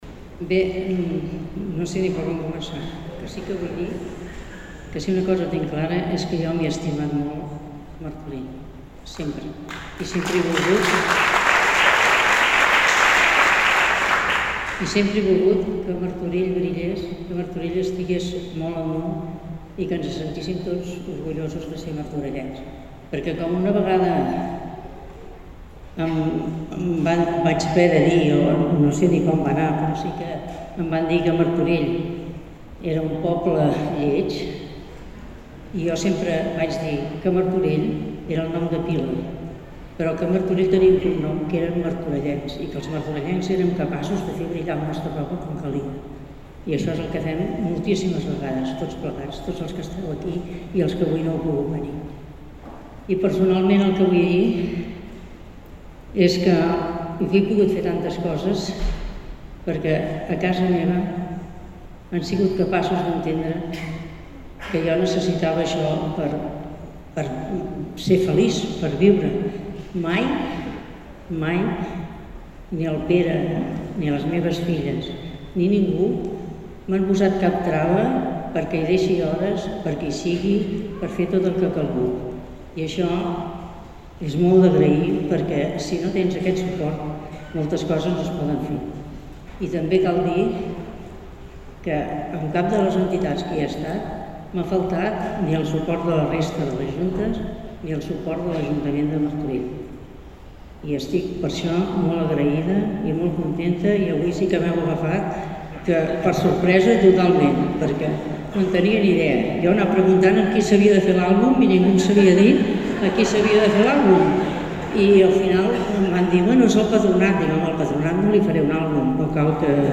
La 29a Trobada d’Entitats ha reconegut l’associacionisme a Martorell aquest vespre a El Progrés, en una gala amb prop de 400 assistents.